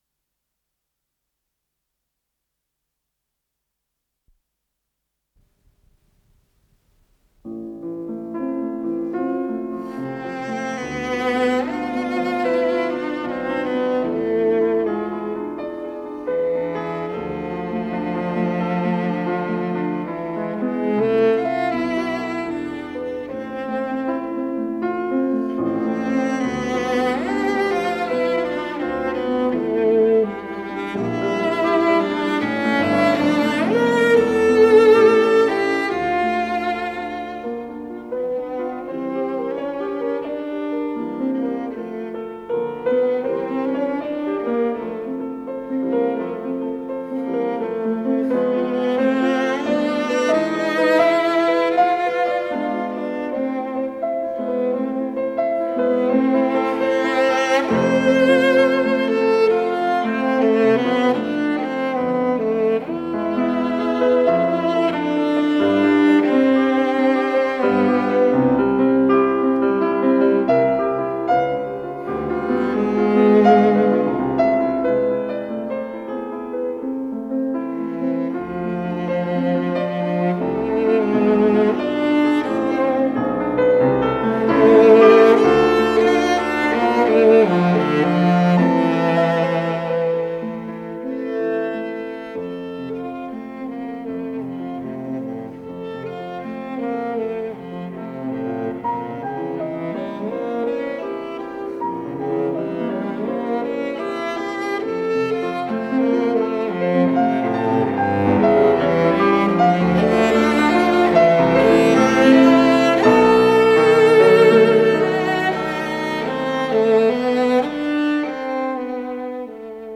Исполнитель: Каринэ Георгиан - виолончель Олег Майзенберг - фортепиано
Для виолончели и фортепиано, части идут без перерыва